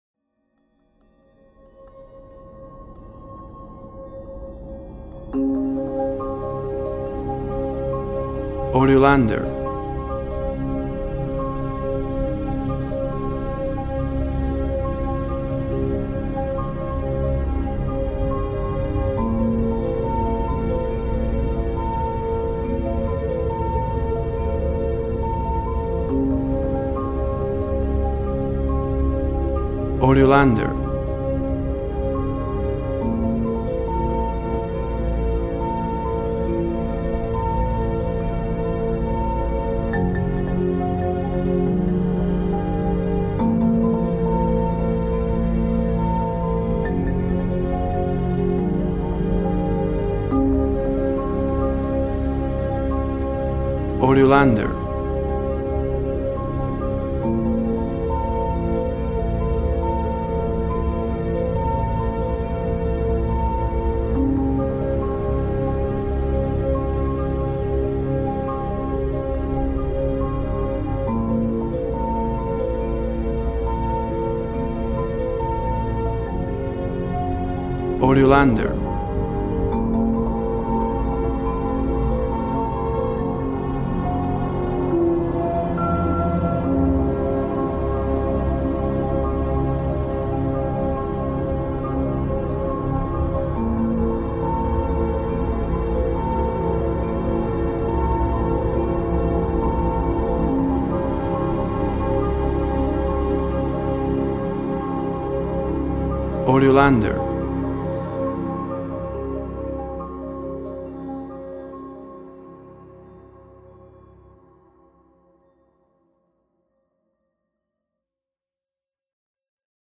Mellow underscore.
Tempo (BPM)  80